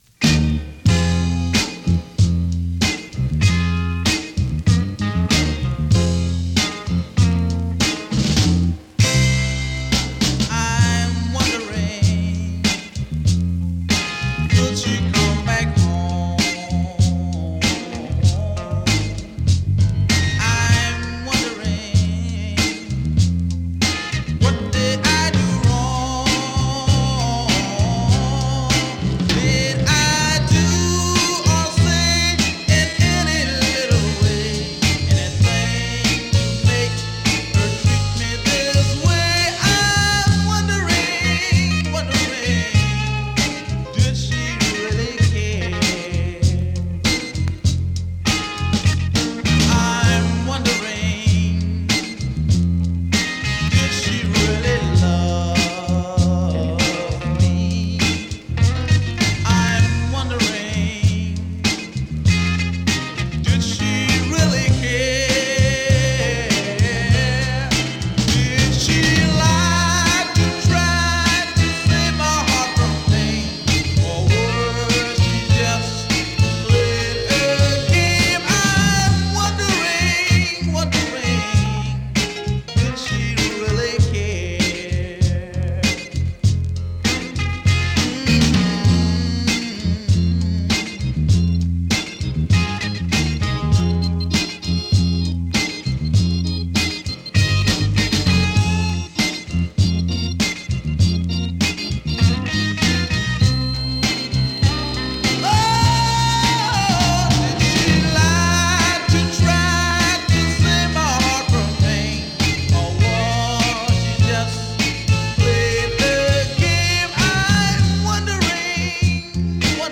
US 45 ORIGINAL 7inch シングル NORTHERN SOUL 試聴
Great Detroit northern soul.
試聴 (実際の出品物からの録音です)